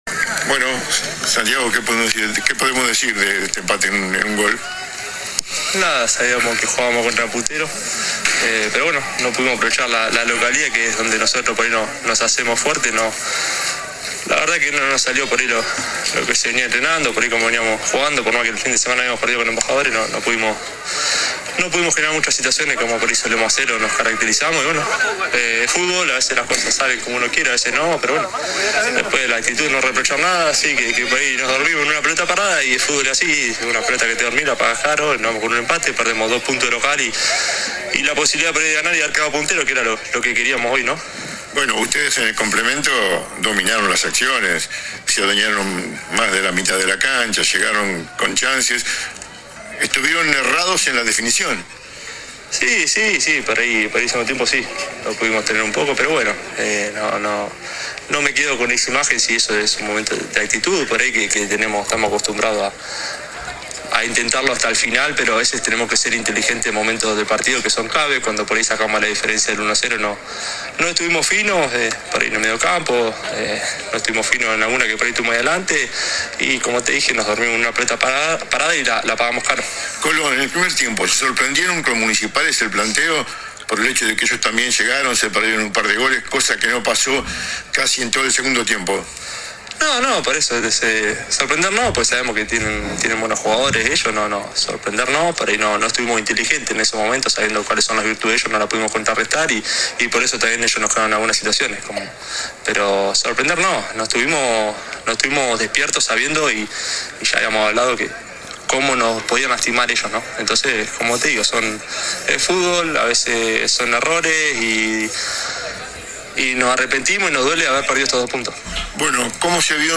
AUDIO DE LA ENTREVISTA